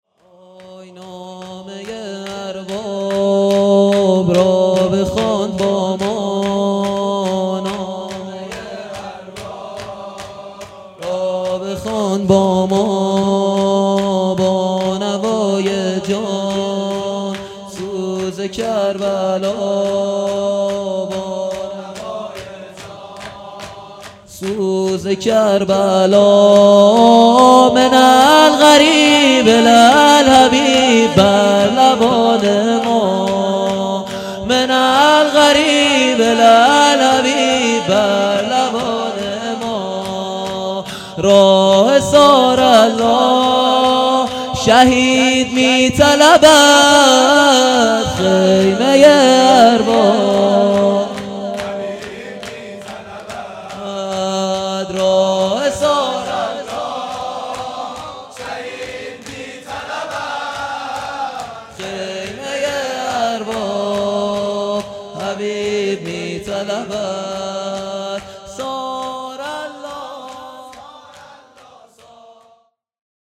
خیمه ارباب حبیب میطلبد _ شور
شب چهارم محرم الحرام 1441